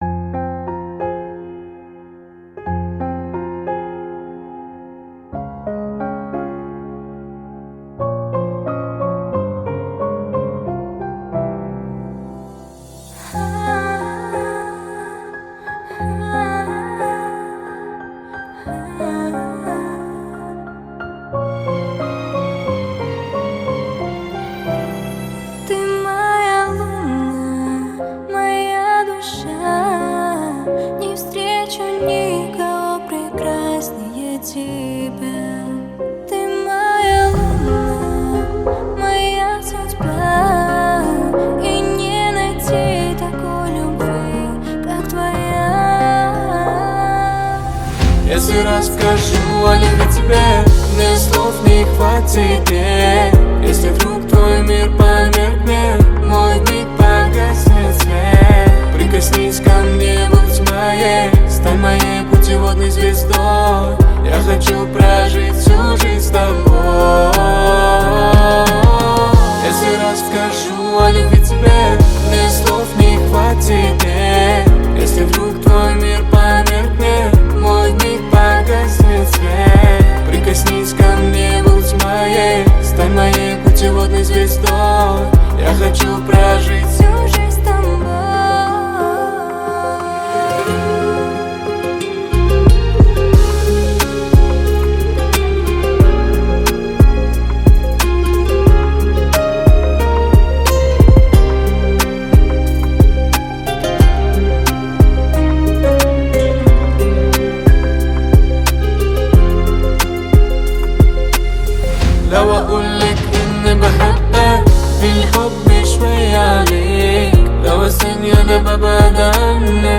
Жанр: Казахские
(cover)